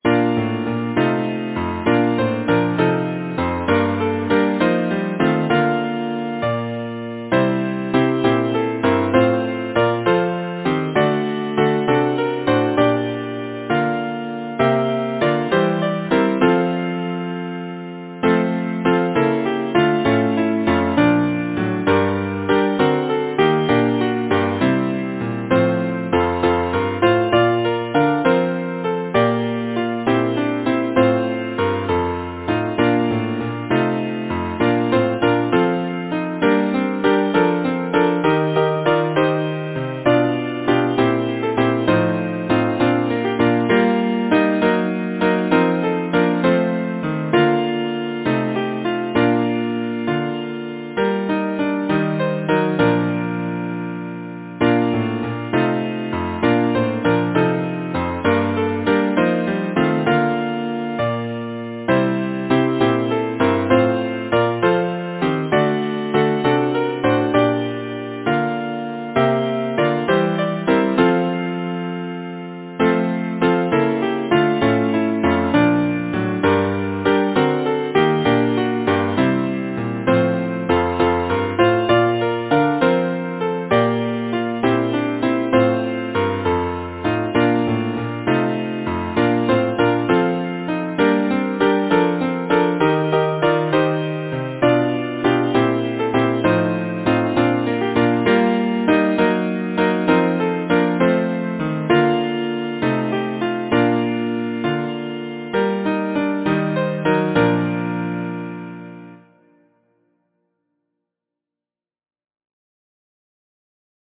Title: Row, gently row Composer: Henry Newboult Lyricist: Edward Oxenford Number of voices: 4vv Voicing: SATB Genre: Secular, Partsong
Language: English Instruments: A cappella